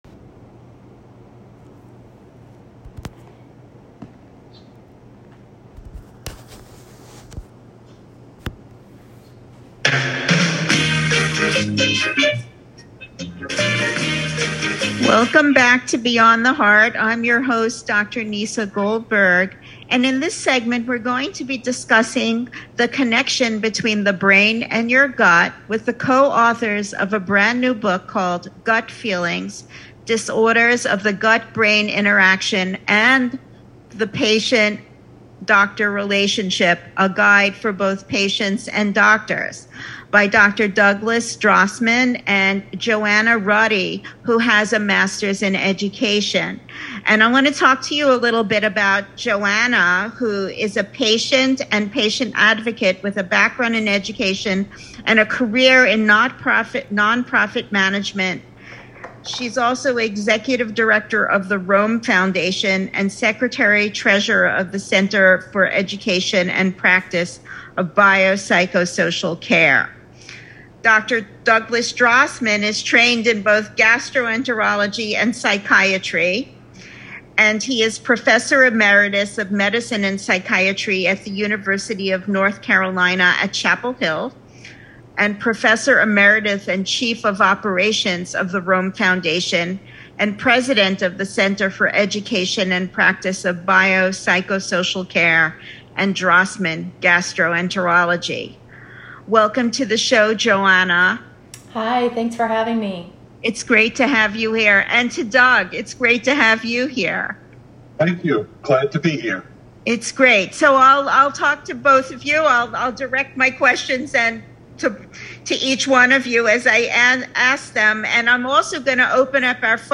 Sirius-Radio-Interview.m4a